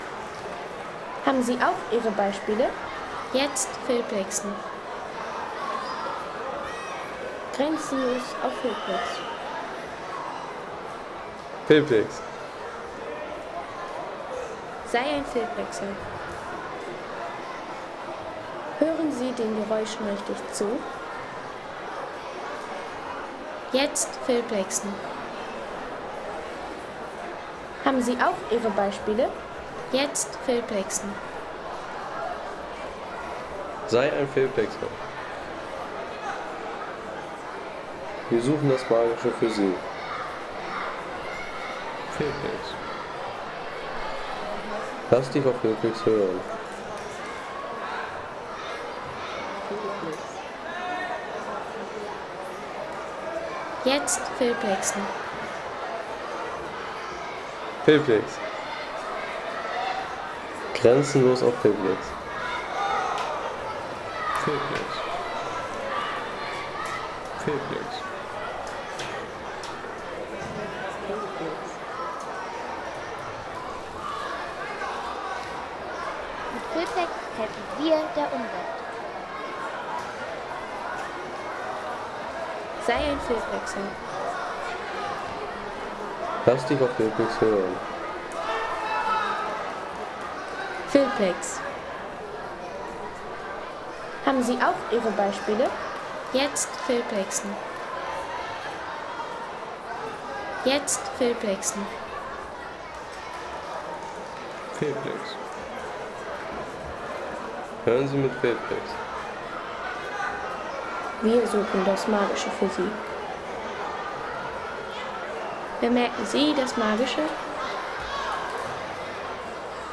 Judo-Turnier